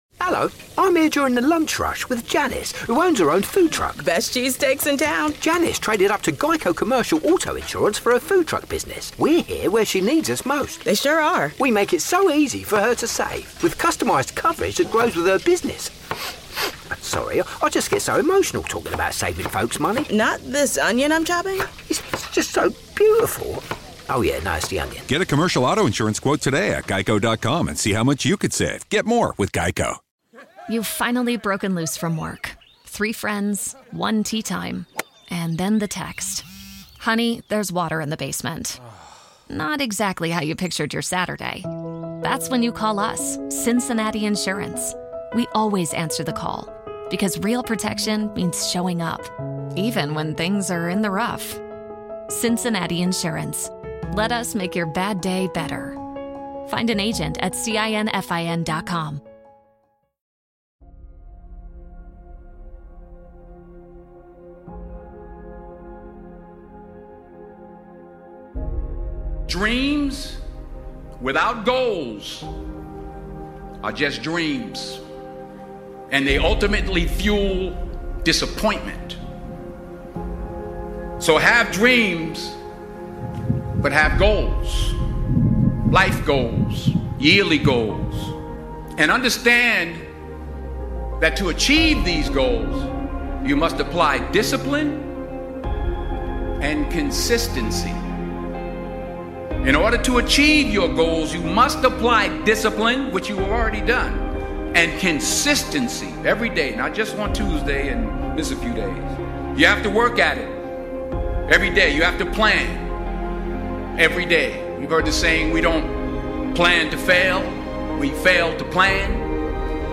Speech by: Denzel Washington